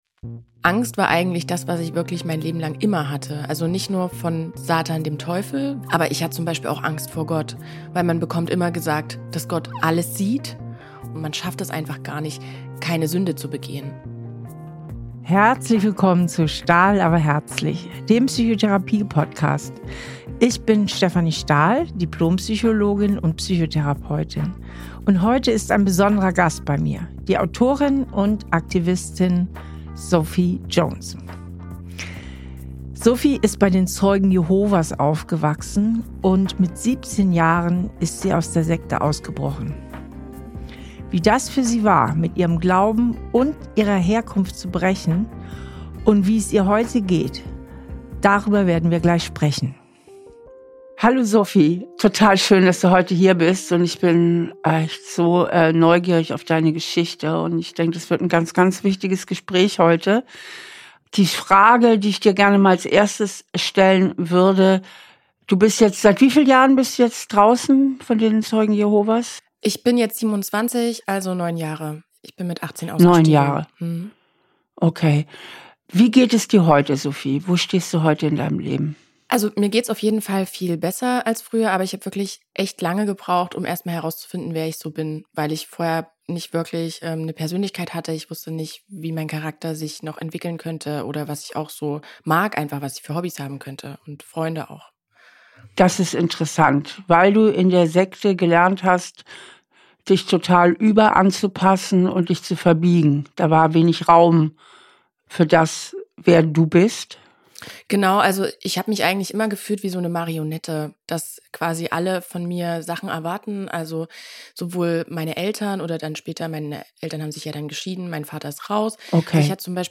zu Gast für eine besondere Interview-Folge.